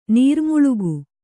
♪ nīrmuḷugu